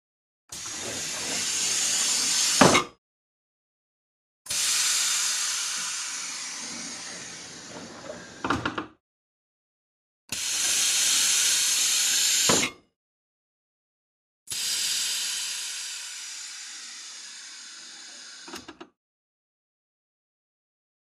Hydraulic Door | Sneak On The Lot
Hydraulic Door; Hydraulically Driven Door Open / Close Two Times, Close Perspective.